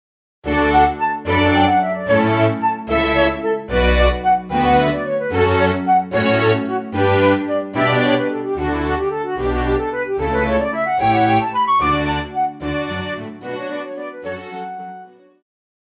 Classical
Concerto,Instrumental
Solo with accompaniment